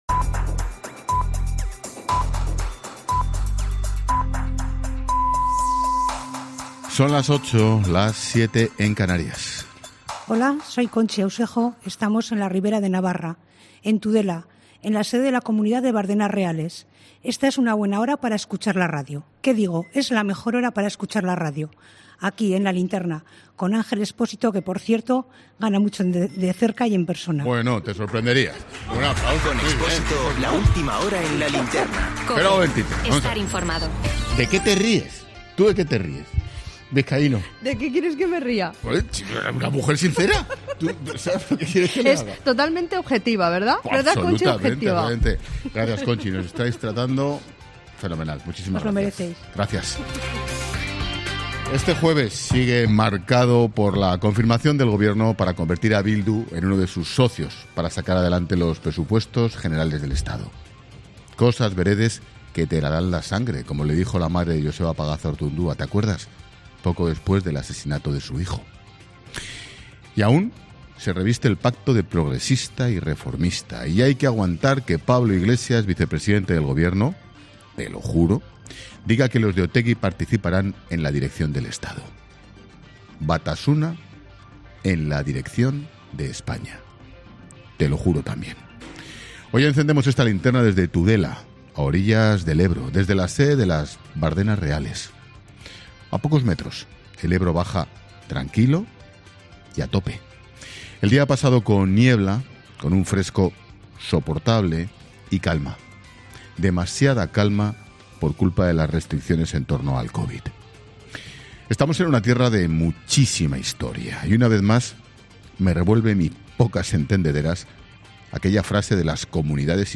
Monólogo de Expósito
El director de 'La Linterna', Ángel Expósito, analiza en su monólogo la evolución de la pandemia y el pacto de Bildu con el Gobierno